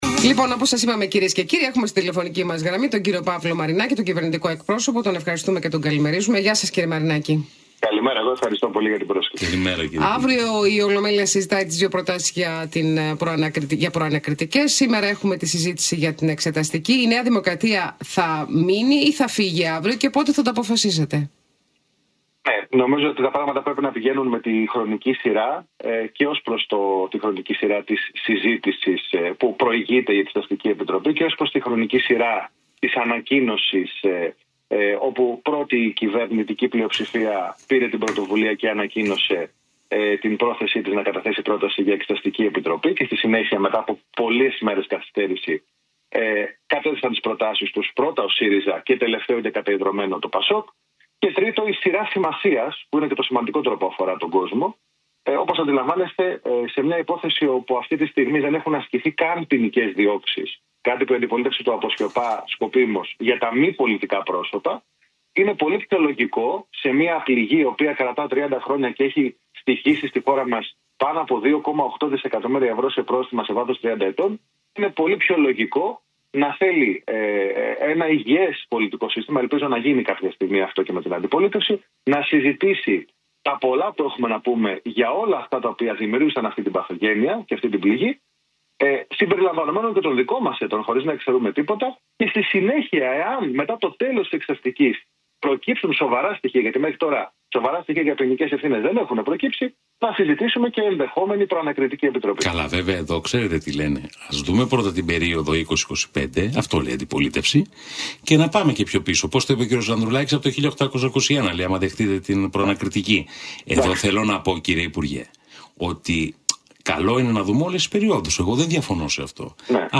Συνέντευξη στον Real FM 97,8 | Γενική Γραμματεία Επικοινωνίας και Ενημέρωσης